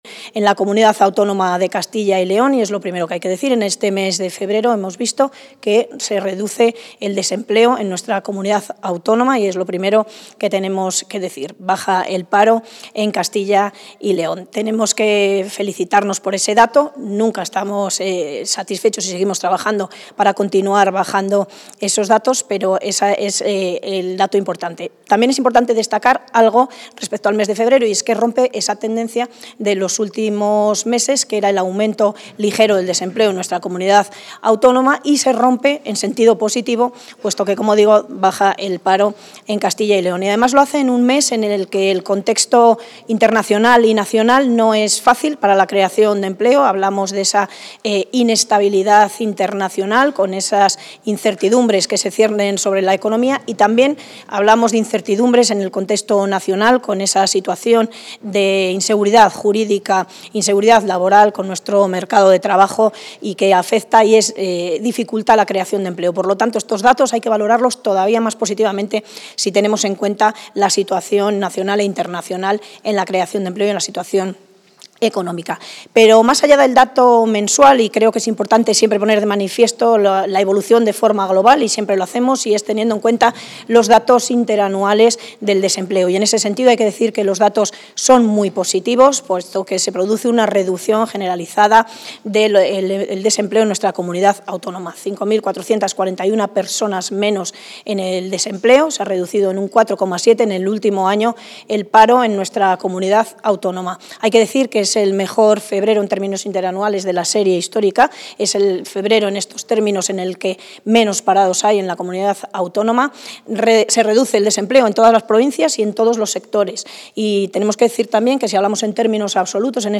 Valoración de los datos del paro registrado de febrero de 2025 Contactar Escuchar 4 de marzo de 2025 Castilla y León | Consejería de Industria, Comercio y Empleo La consejera de Industria, Comercio y Empleo, Leticia García, ha valorado hoy los datos de paro registrado correspondientes al mes de febrero de 2025.